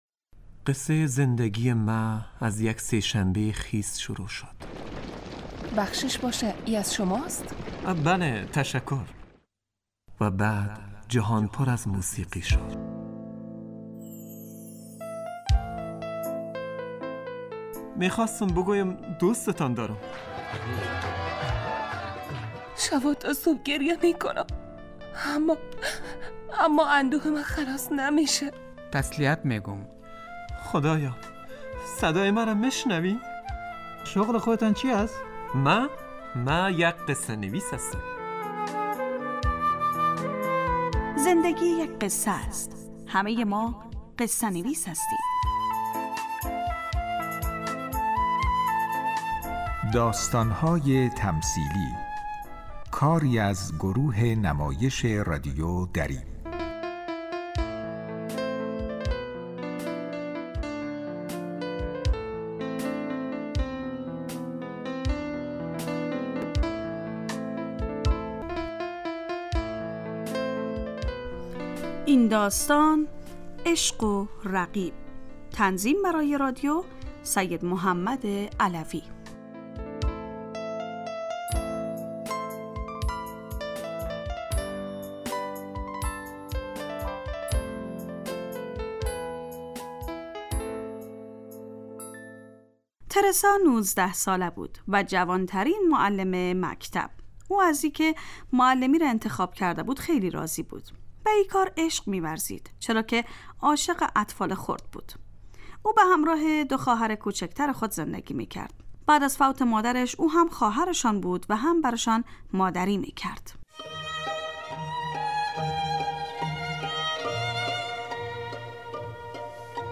داستانهای تمثیلی نمایش 15 دقیقه ای هستند که هر روز ساعت 3:30 عصربه وقت وافغانستان پخش می شود.